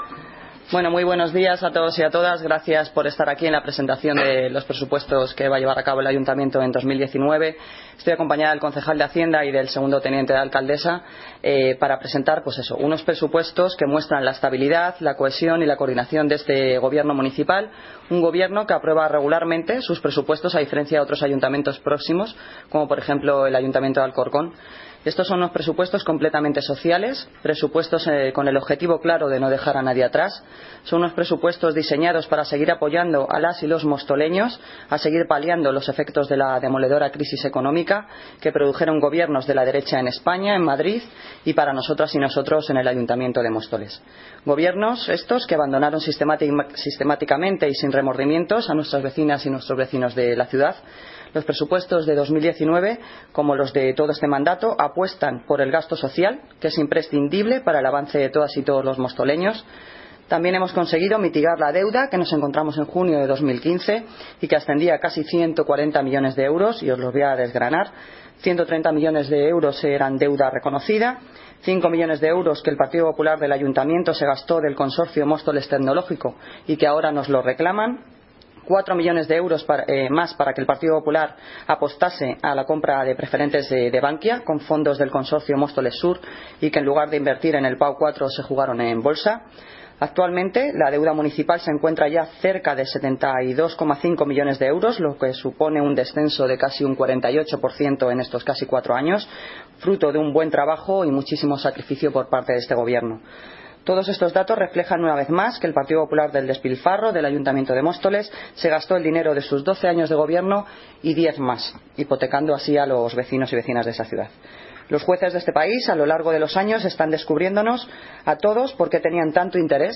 Audio - Noelia Posse (Alcaldesa de Móstoles) Sobre Presupuestos Ayuntamiento 2019